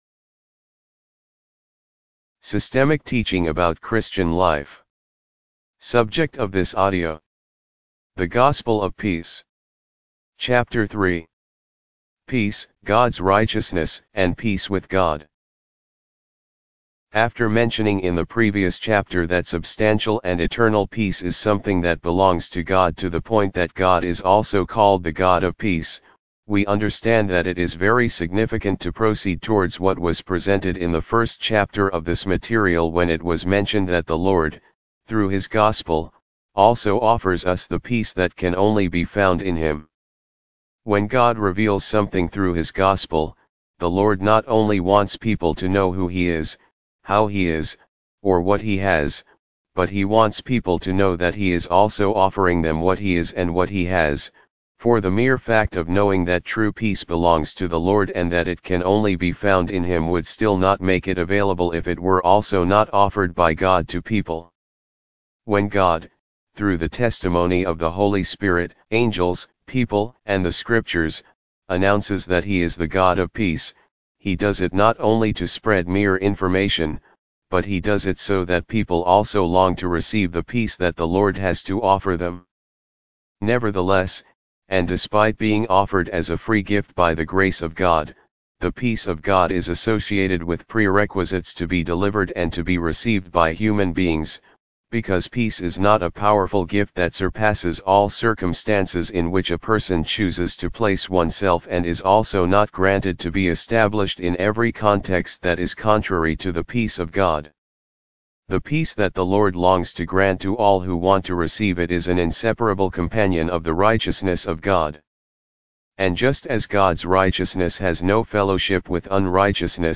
Audibooks - Systemic Teaching about Christian Life Audio Book divided into chapters.